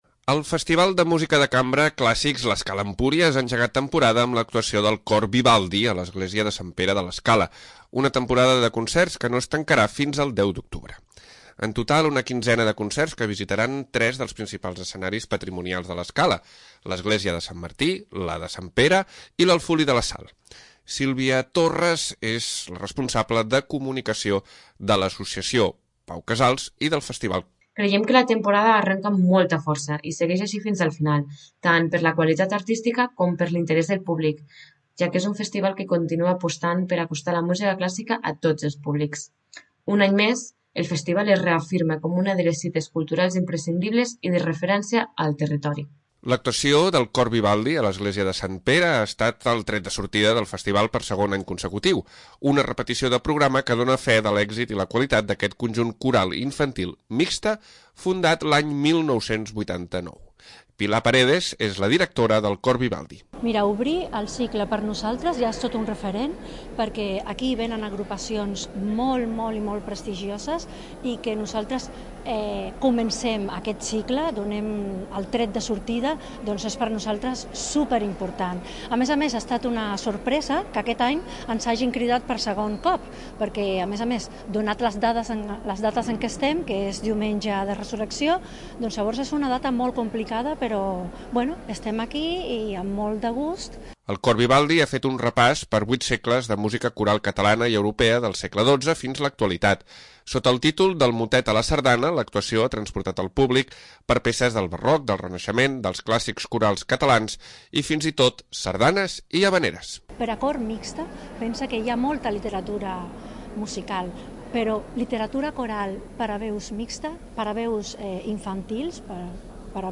El Festival de música de cambra Clàssics l'Escala-Empúries ha engegat temporada amb l'actuació del Cor Vivaldi a l'Església de Sant Pere de l'Escala, una temporada de concerts que no es tancarà fins el 10 d'octubre.
Una repetició de programa que dona fe de l'èxit i la qualitat d'aquest conjunt coral infantil mixte fundat l'any 1989.
Sota el títol del Motet a la Sardana, l'actuació ha transportat al públic per peces del barroc, del reinaxement, dels clàssics corals catalans i fins i tot sardanes i havaneres.